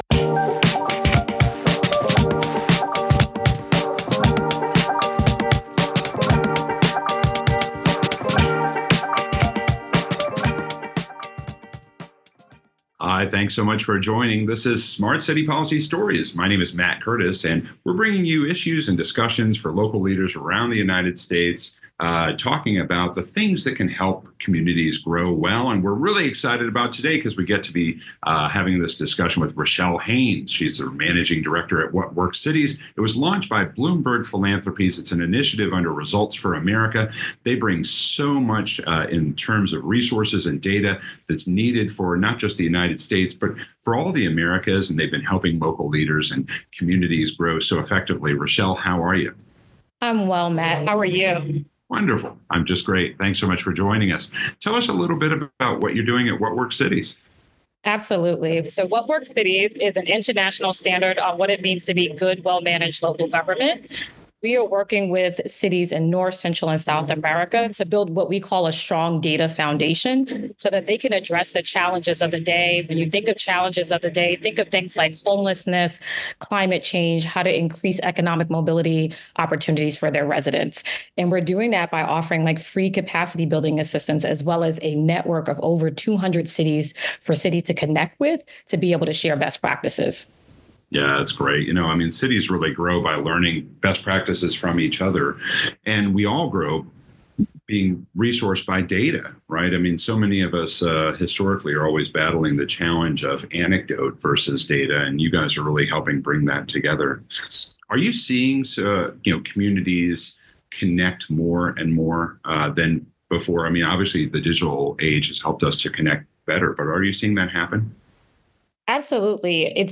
Interviews What Works Cities - by Bloomberg philanthropies, and Results for America